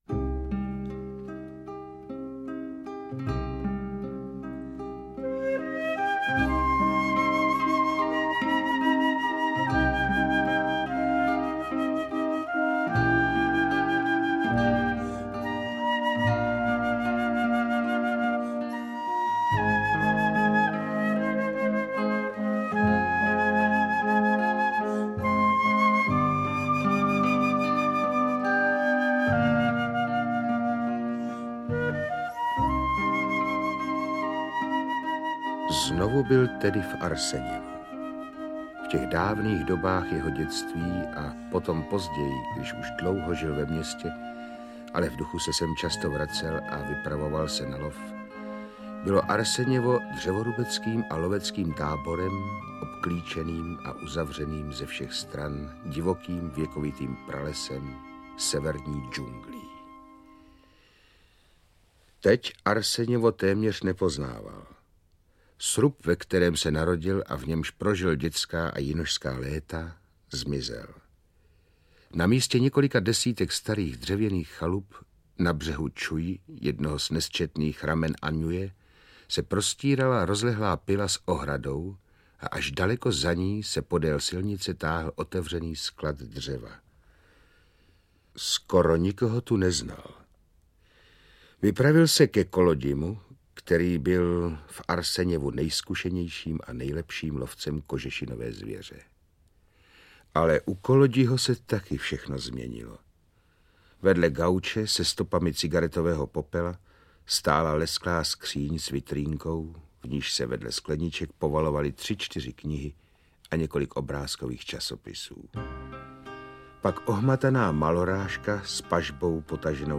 • AudioKniha ke stažení Kozák: Podzim v kraji tygrů
Původní LP album "Jan Kozák Podzim v kraji tygrů" vydal Supraphon v roce 1982 - výběr z tohoto "loveckého díla", milostného příběhu dvou geologů z prostředí sibiřské tajgy v interpretaci Pavla Haničince nyní vychází poprvé digitálně.